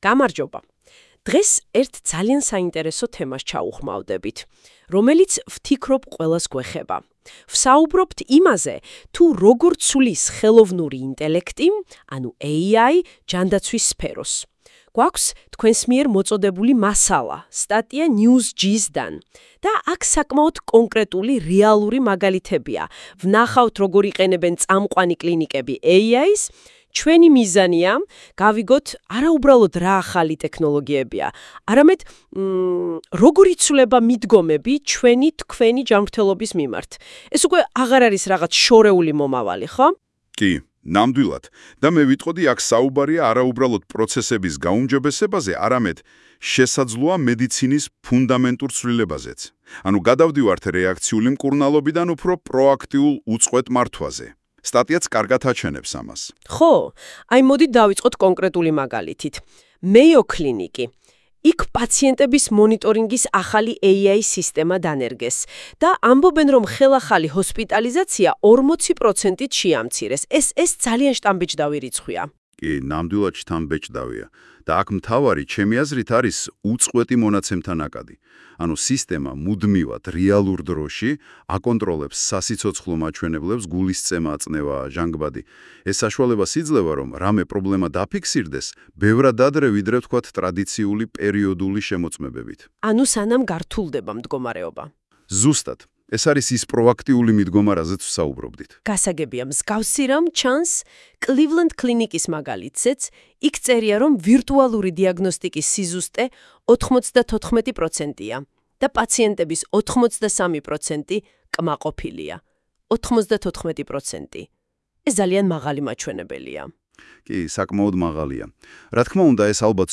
აქ შეგიძლიათ მოუსმინოთ პოდკასტს ამ სტატიის ირგვლივ რომელიც ხელოვნური ინტელექტის მეშვეობითაა გენერირებული.